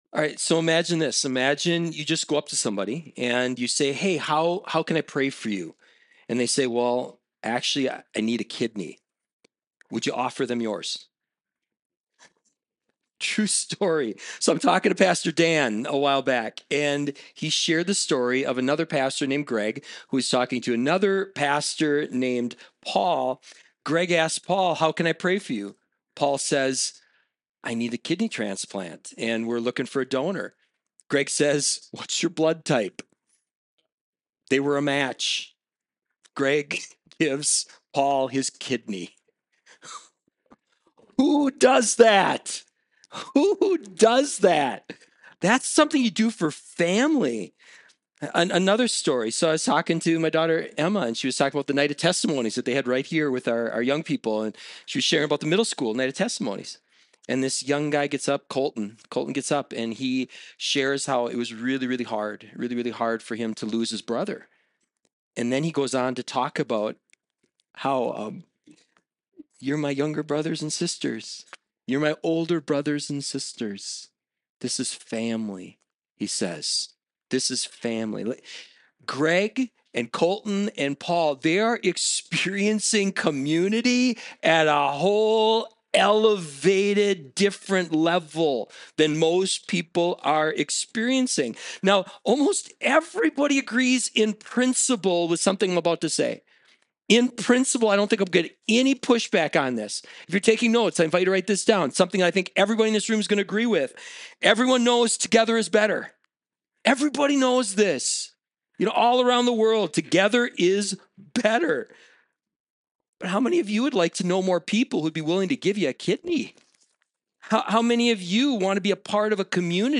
Weekly messages from Emmanuel Covenant Church, Twin Cities, MN.